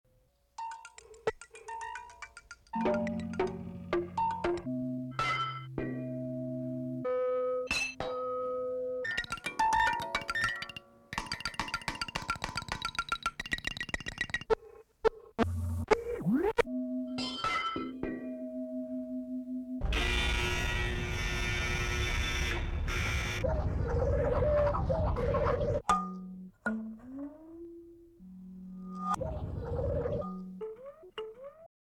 musique concrète